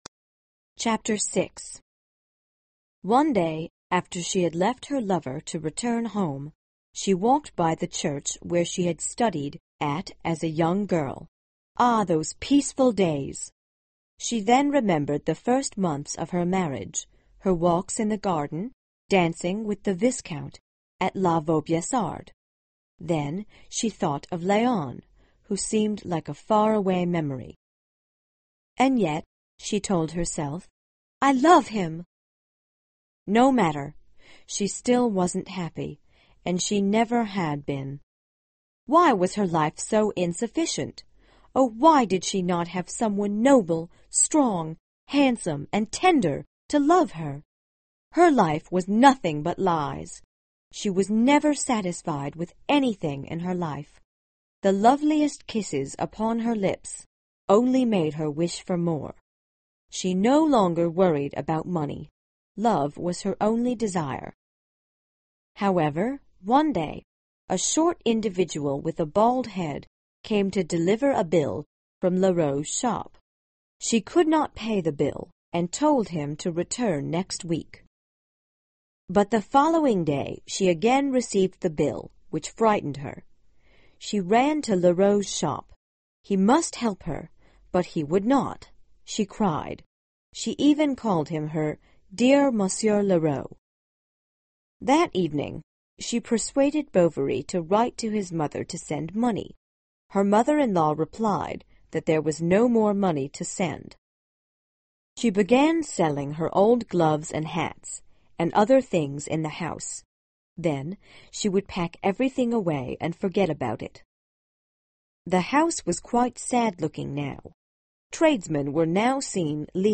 有声名著之包法利夫人 306 听力文件下载—在线英语听力室